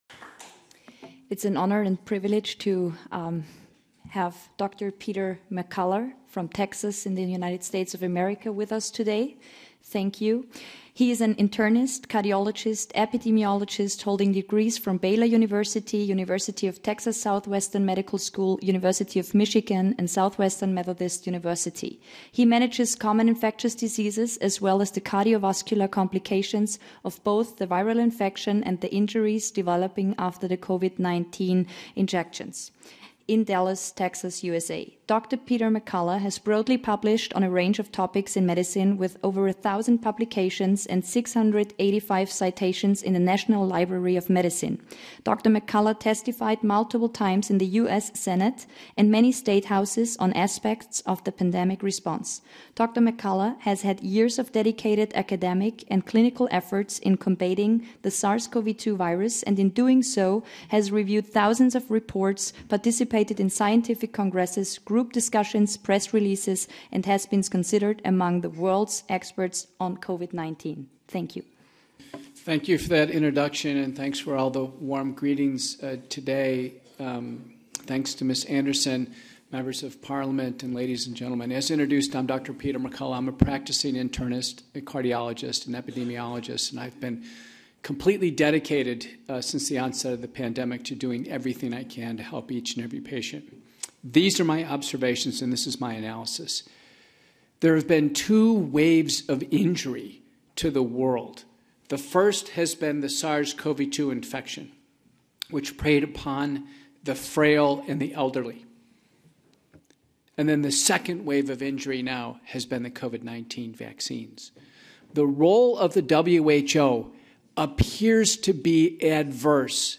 Dr. McCullough's Speech at the European Parliament